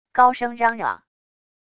高声嚷嚷wav音频